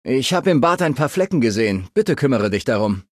Datei:Maleadult01default convandale hello 0001b03e.ogg
Fallout 3: Audiodialoge